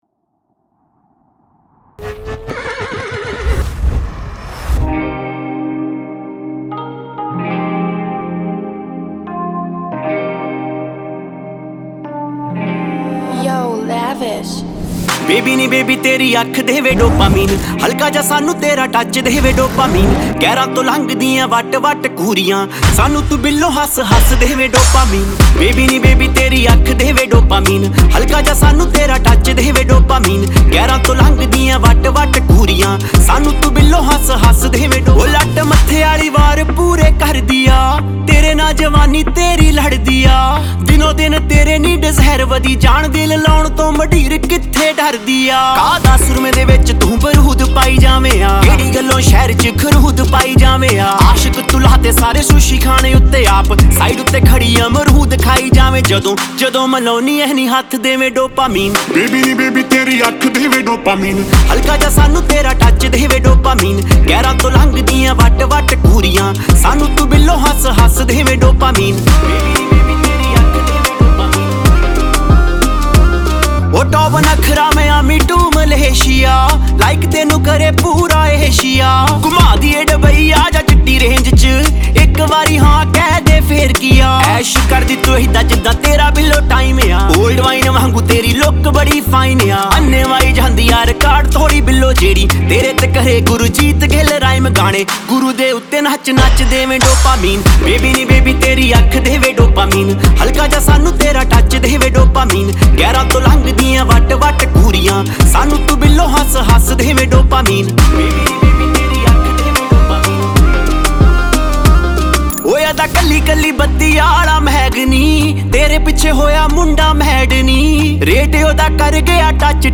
Song TypePunjabi Pop